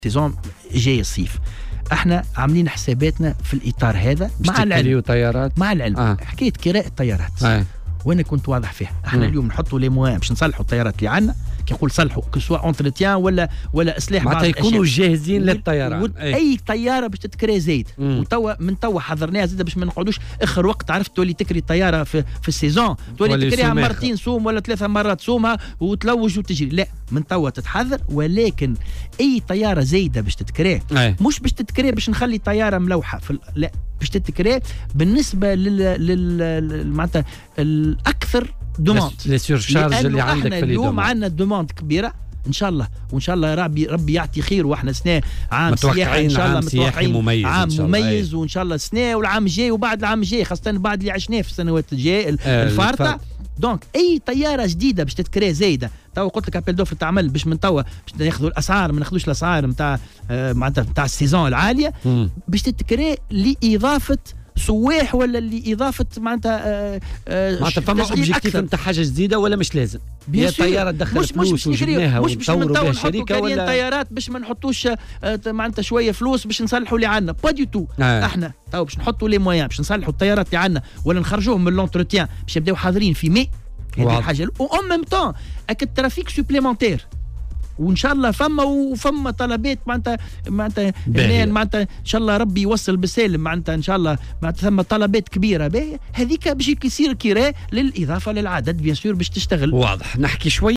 وأوضح ضيف "بوليتيكا" على "الجوهرة اف أم"، أن هذا الإجراء سيتم بالتوازي مع عمليات الصّيانة الجارية حاليا لأسطول طائرات الخطوط التونسية والتي ستكون جاهزة بداية من ماي المقبل.